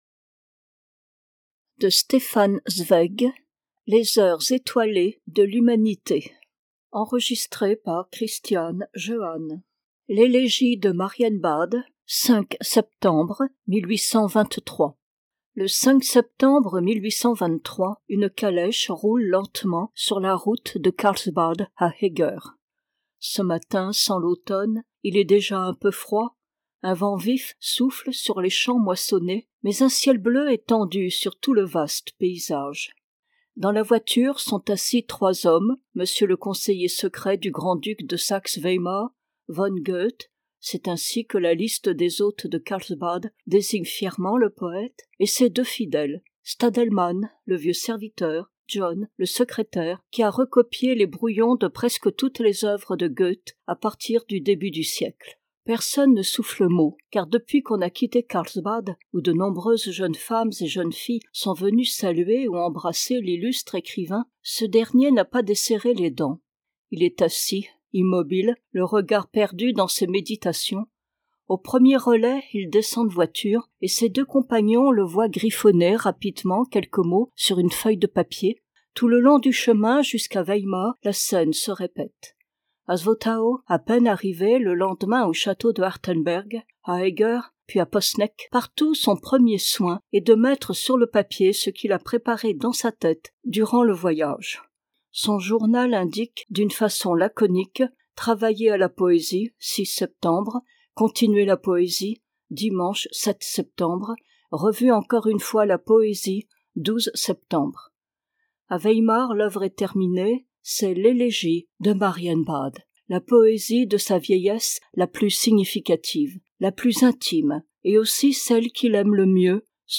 Livre audio gratuit : Les Heures étoilées de l'humanité, Elégie de Marienbad - Audecibel